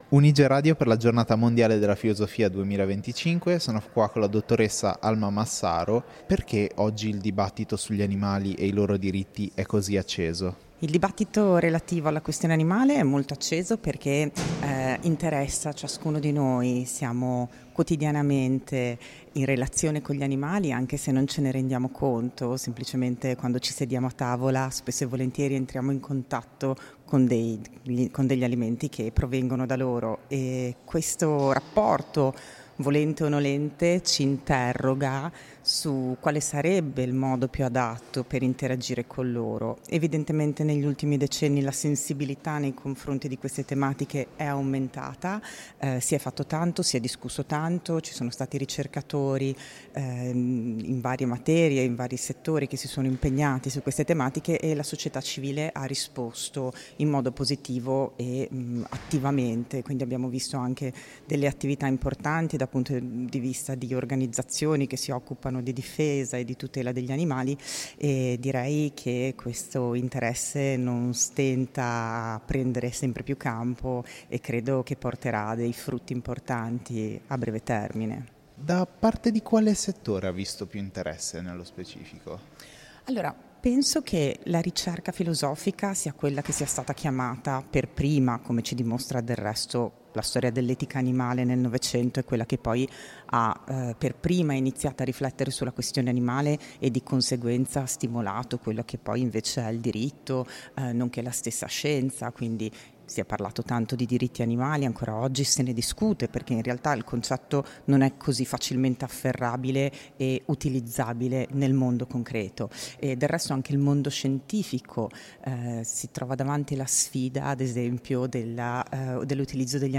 Un dialogo che invita a ripensare il rapporto uomo-animale senza risposte semplici, ma con strumenti critici condivisi.